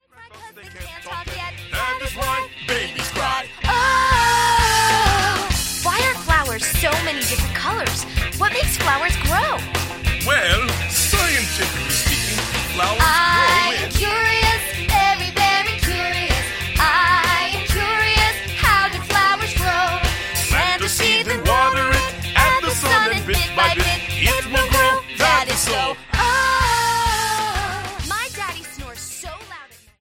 peppy song
• MP3 of both vocals and instrumental